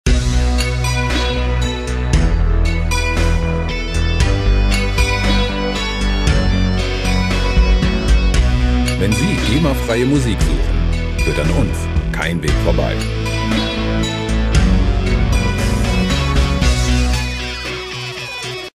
Industrial Synth Rock